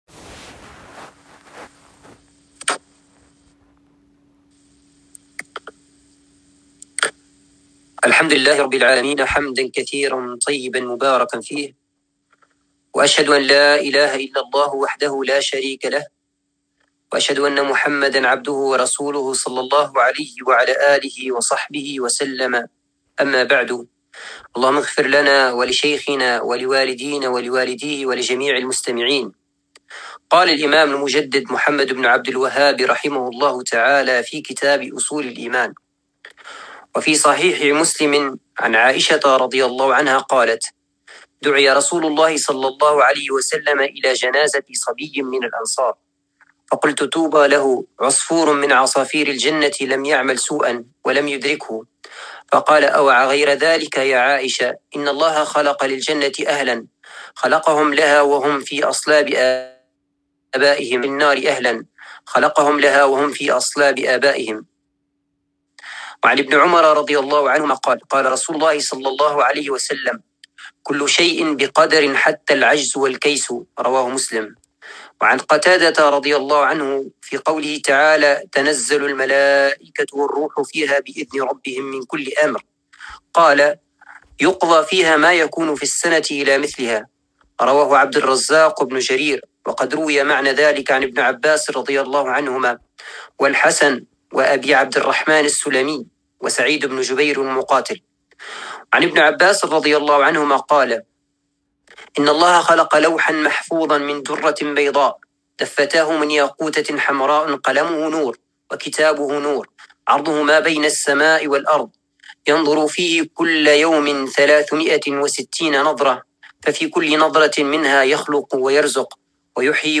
الدرس الخامس من كتاب أصول الإيمان